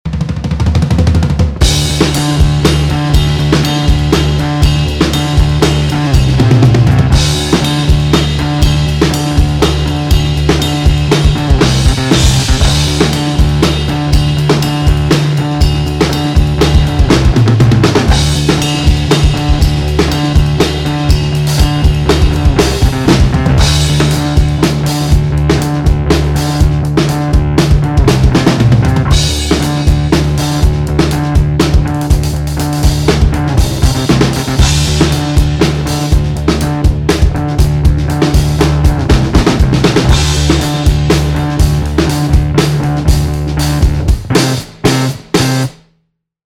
The Trip Top is the opposite – there’s still a nice smooth top end -and plenty of it – but this amp is all about low end girth and grind.